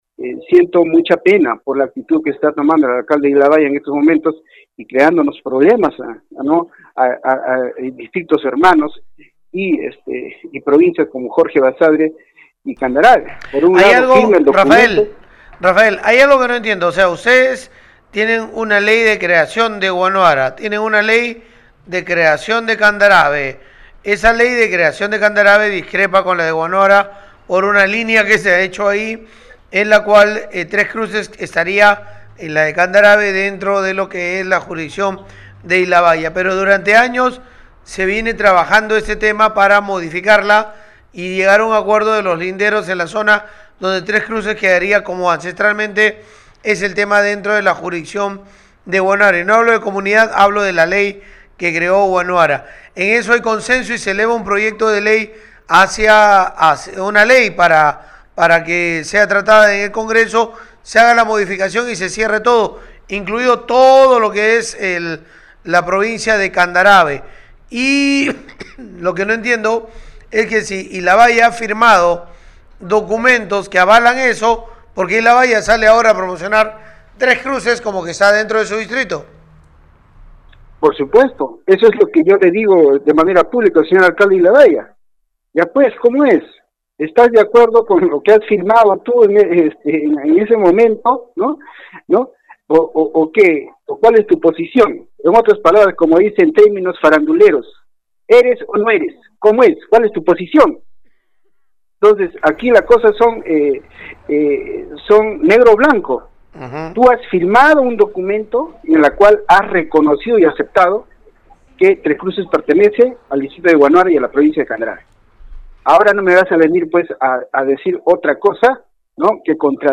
Radio Uno también conversó con Rafael Vargas, alcalde de la provincia Candarave, quien exigió a Juan Santos Ordoñez Miranda, alcalde de Ilabaya, dar su descargo «eres o no eres, cuál es la posición», reclamó al mismo tiempo de reiterar que Tres Cruces le pertenece a Huanuara.
(Audio: Rafael Vargas, alcalde de Candarave)